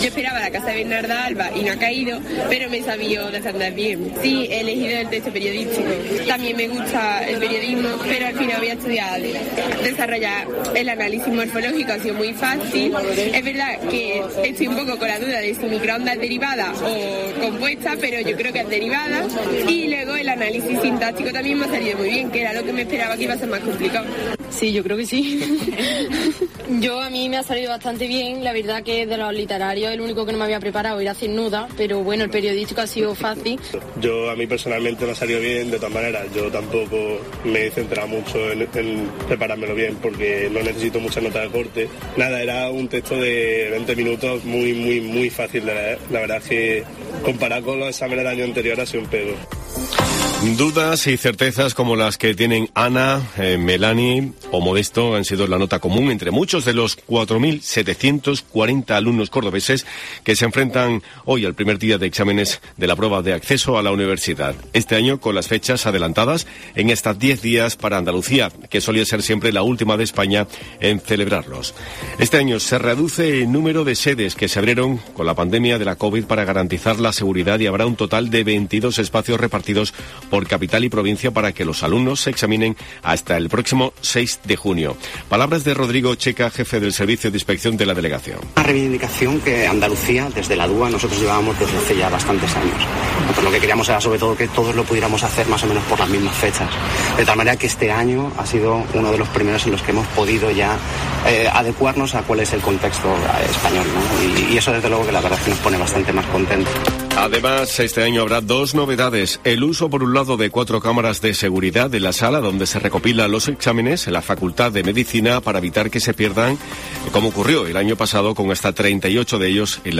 Así suena el primer día de la PEvAU en Córdoba